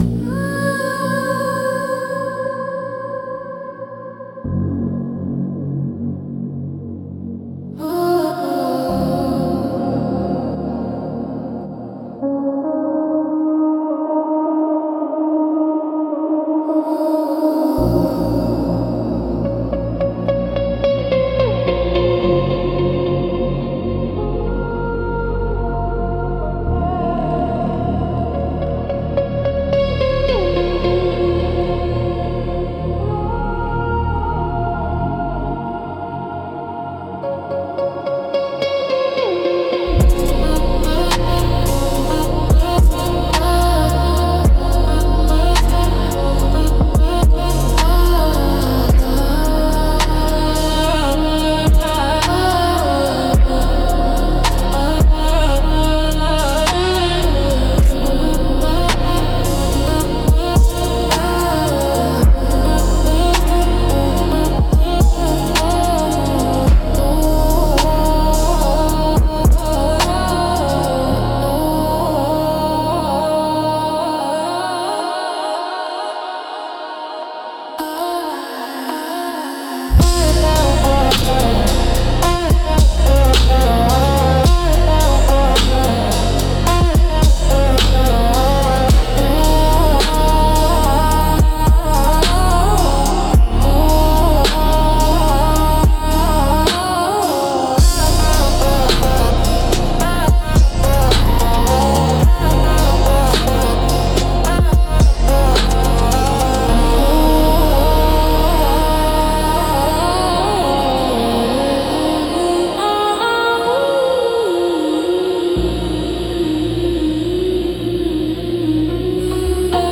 Instrumental - Arp of Letting Go 3.44